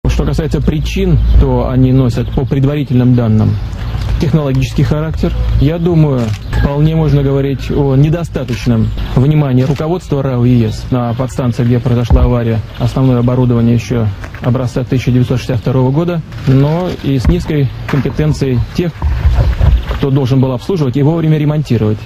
Mówi Władimir Putin